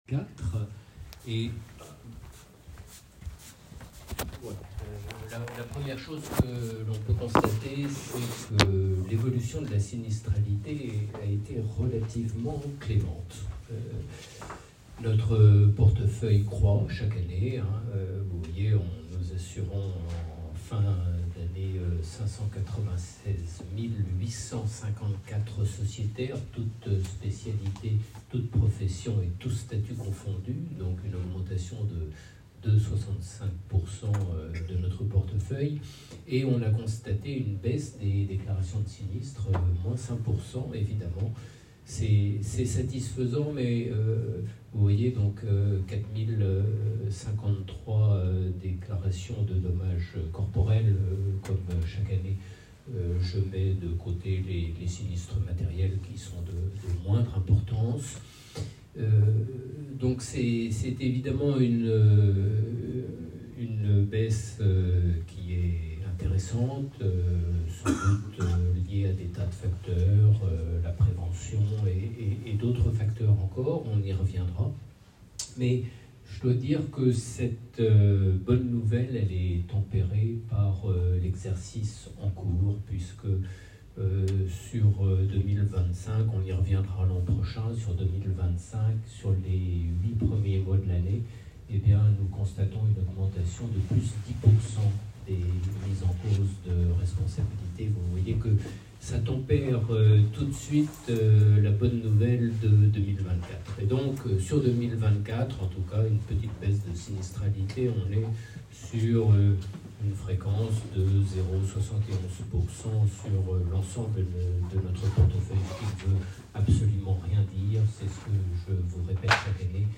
[AUDIO] Conférence de presse MACSF - Responsabilité médicale du 23 sept 2025